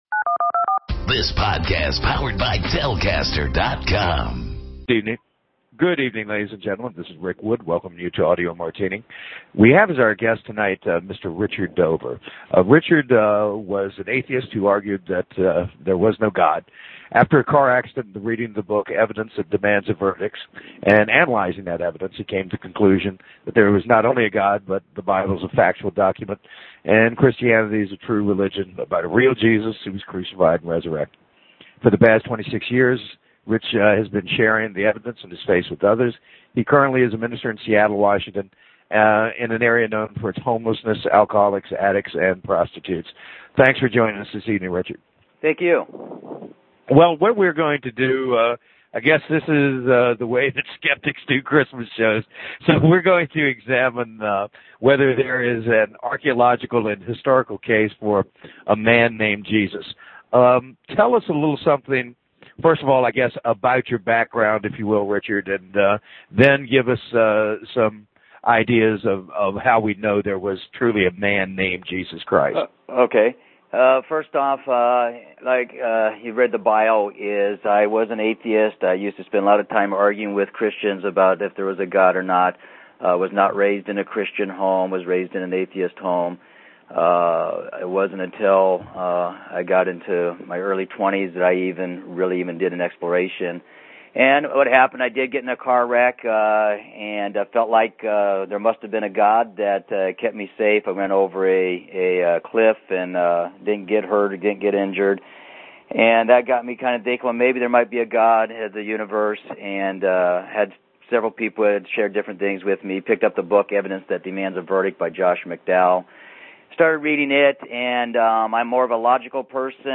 Skeptics Interview